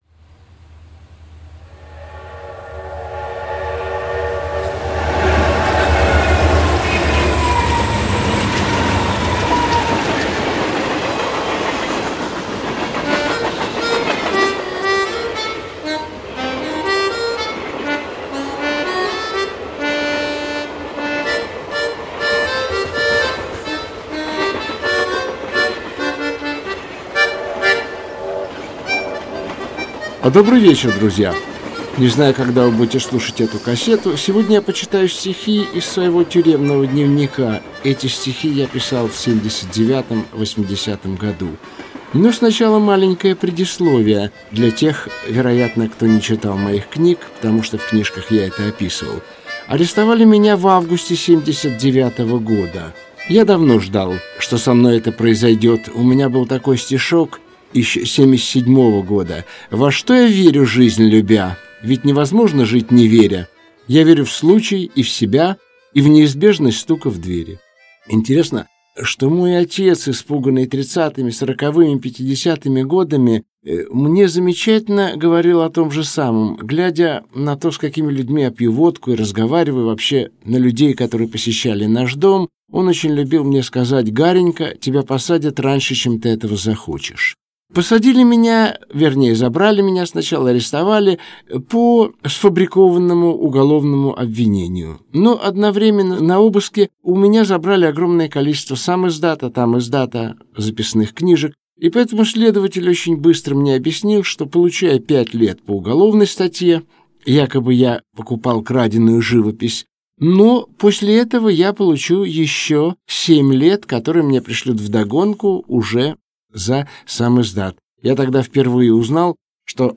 Aудиокнига Тюремные Гарики Автор Игорь Губерман Читает аудиокнигу Игорь Губерман.